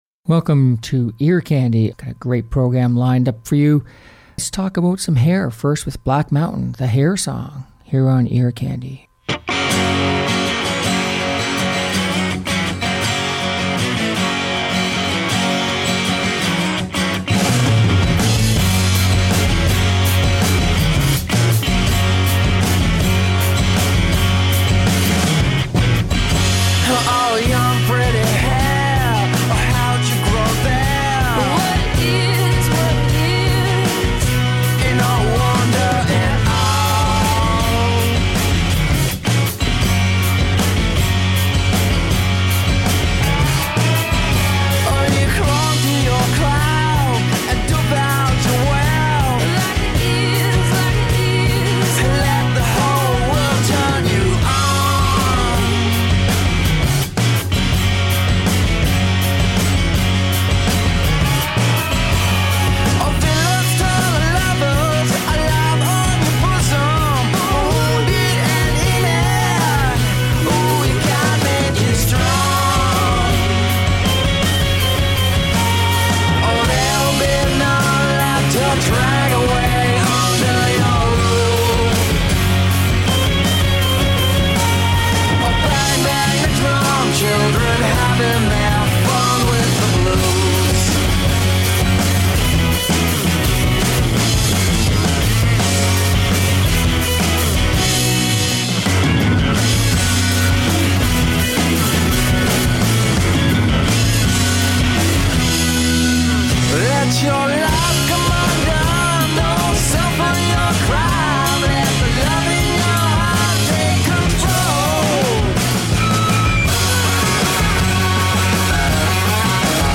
Catchy Uptempo Music From Old and New Artists